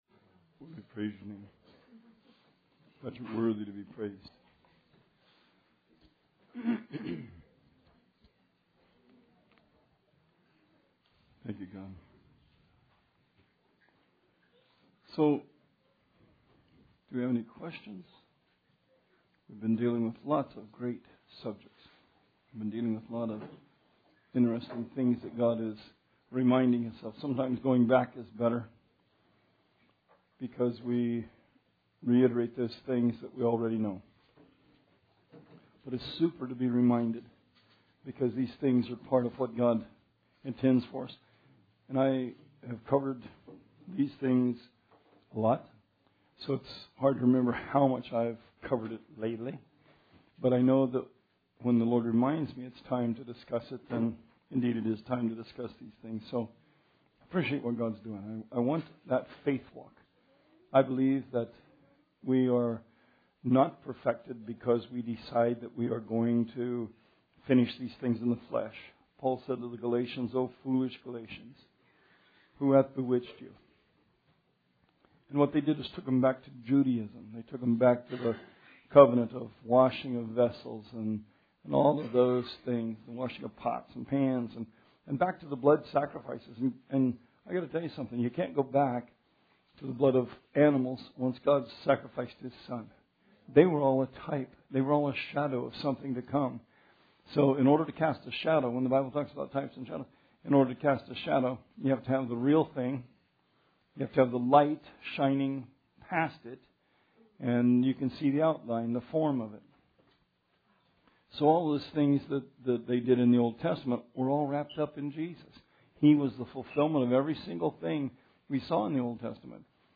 Bible Study 4/20/16